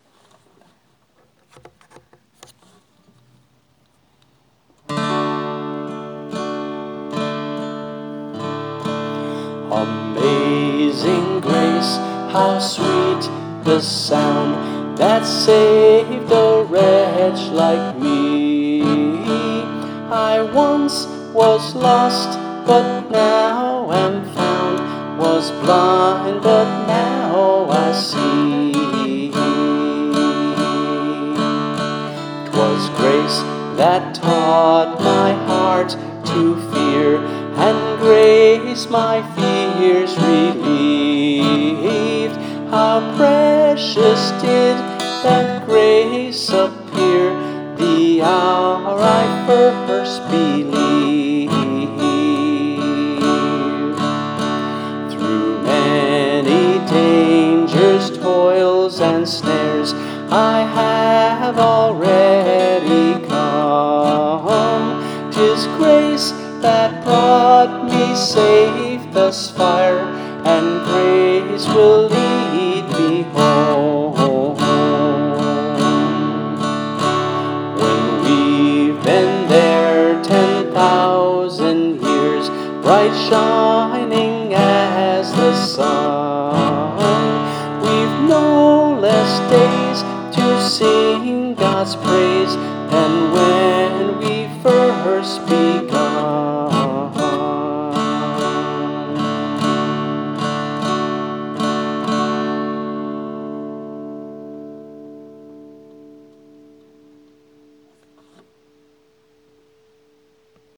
(four part round)